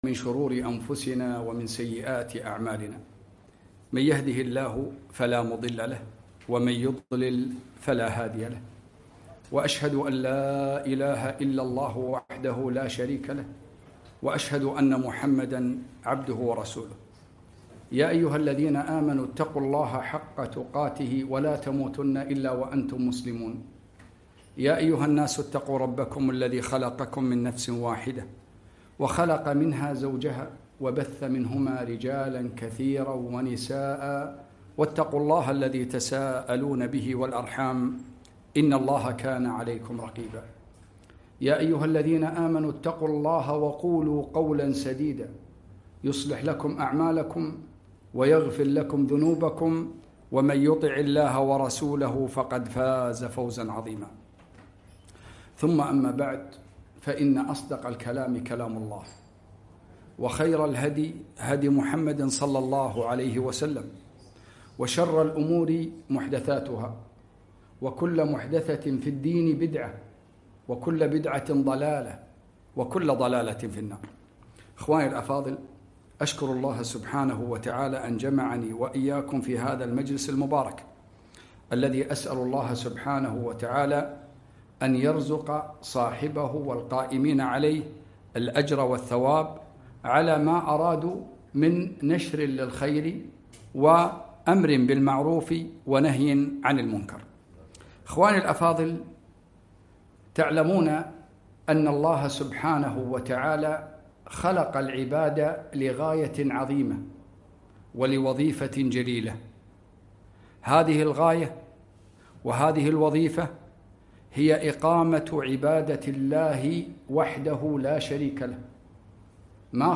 محاضرة - حفظ النعم بشكرها